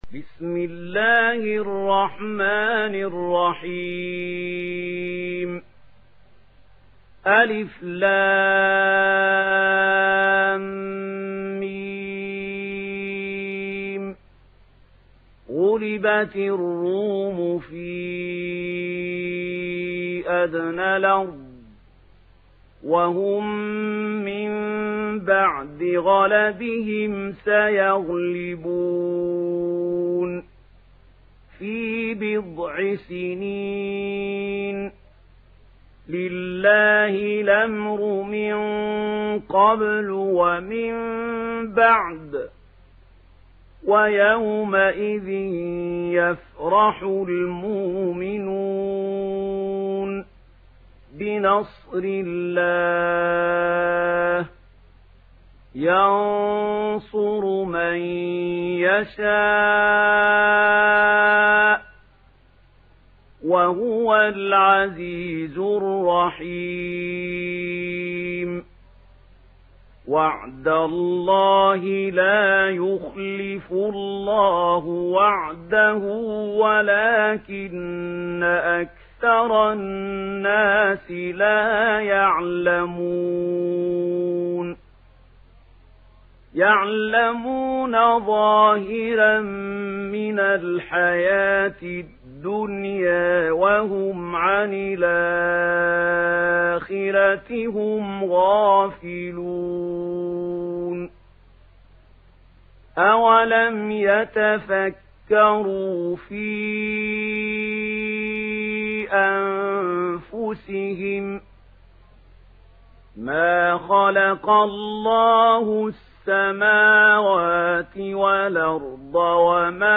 Sourate Ar Rum Télécharger mp3 Mahmoud Khalil Al Hussary Riwayat Warch an Nafi, Téléchargez le Coran et écoutez les liens directs complets mp3